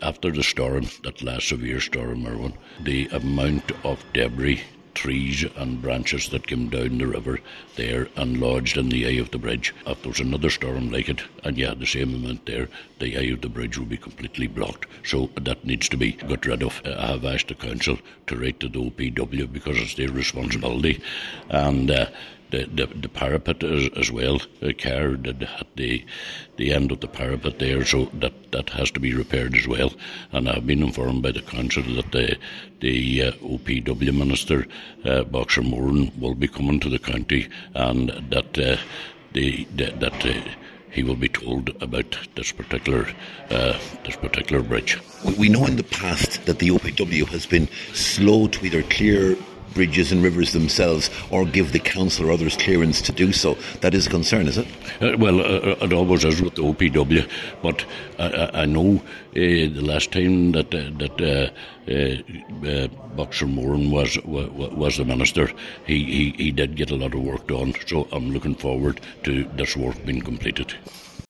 Cllr Coyle says the work needs to be done quickly………